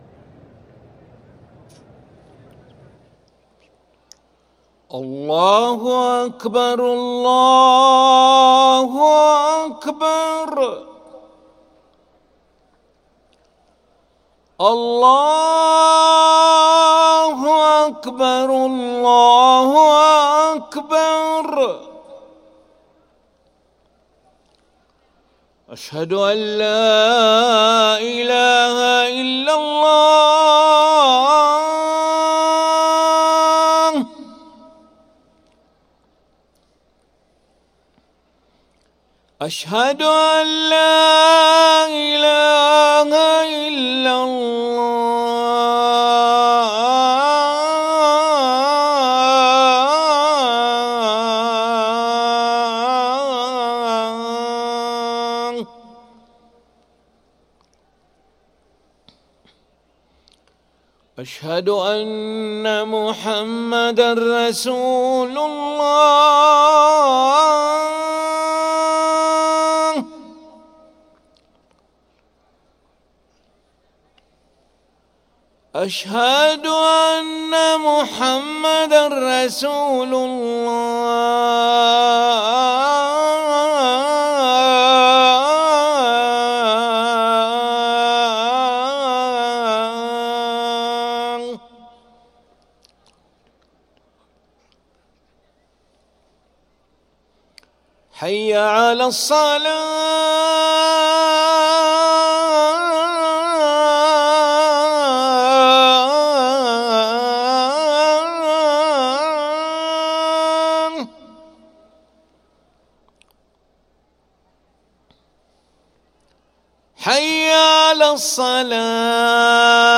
أذان العشاء للمؤذن علي أحمد ملا الأحد 22 ذو القعدة 1444هـ > ١٤٤٤ 🕋 > ركن الأذان 🕋 > المزيد - تلاوات الحرمين